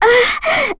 Lilia-Vox_Damage_03.wav